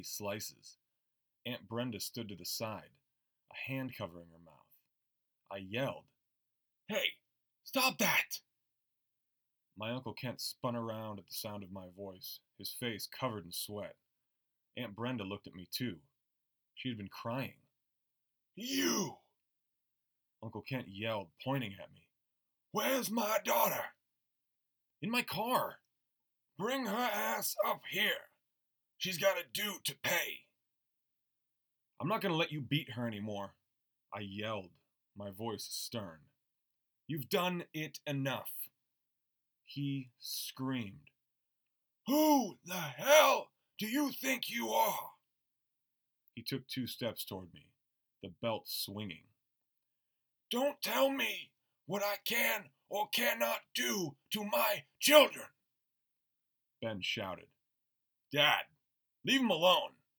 I then realize I somehow recorded several of the books using the built in microphone on my laptop…
Just from first pass of your test clip, it has compression distortion from the laptop built-in Noise Reduction and Echo Cancellation. That gives you cellphone voice and may cause your submission to be rejected for Voice Quality issues, not Technical Quality.
Unfortunately yes thats raw…
I can force the clip to pass ACX Technical Conformance, but it still sounds like talking into a wine glass; harsh, piercing sibilance and pumping background noise. All typical of a normal laptop microphone.